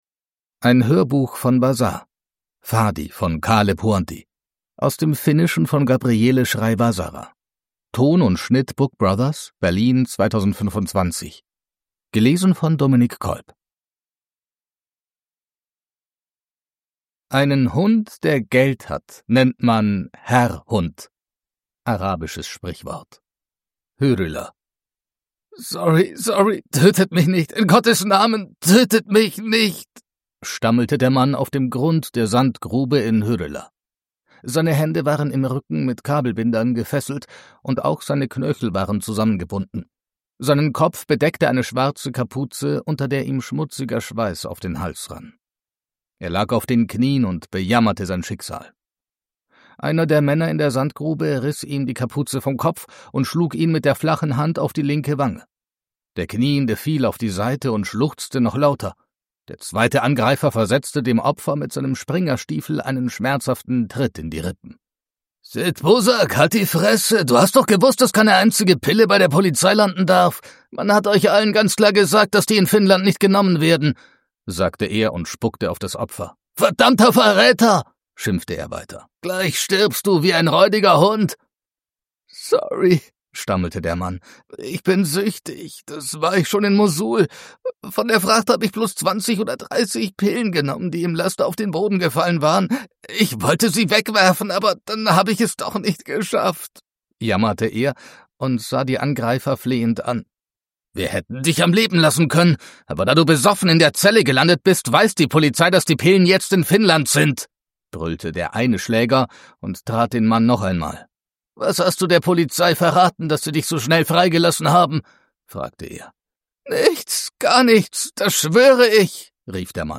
Fadi – Ljudbok